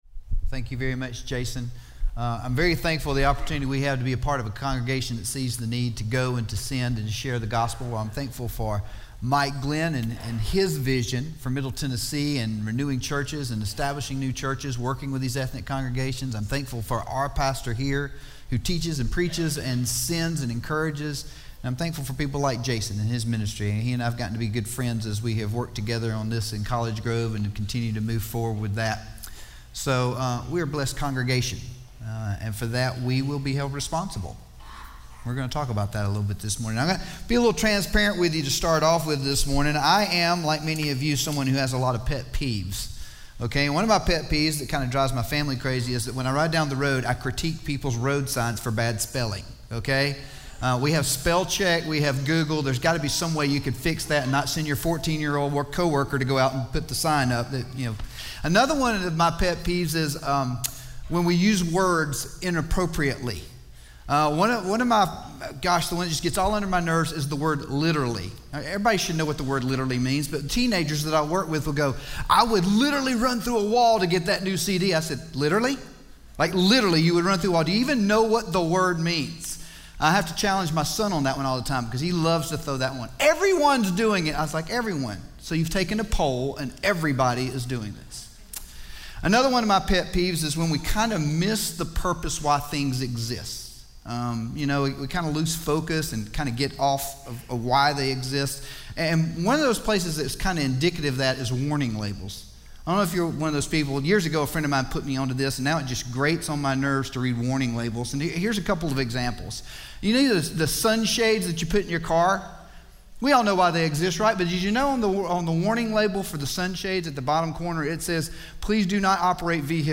The Church Sent - Sermon - Station Hill